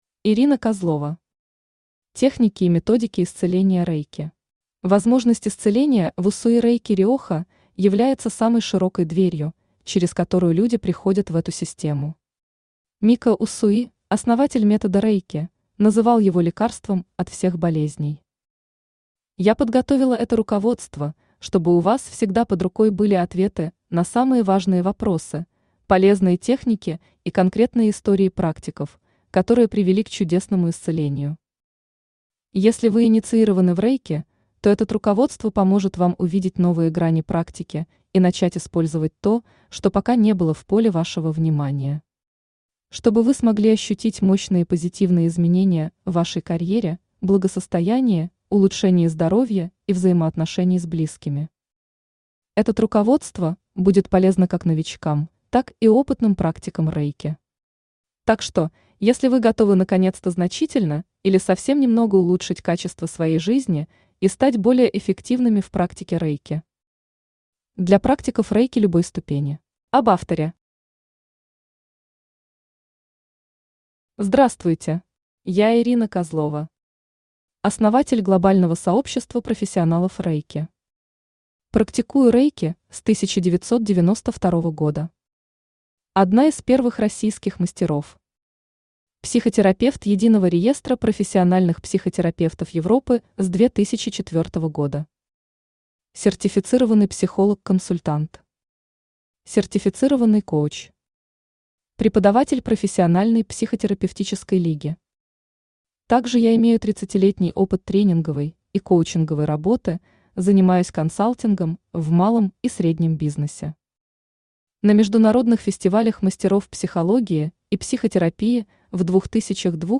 Аудиокнига Техники и методики исцеления Рэйки | Библиотека аудиокниг
Aудиокнига Техники и методики исцеления Рэйки Автор Ирина Александровна Козлова Читает аудиокнигу Авточтец ЛитРес.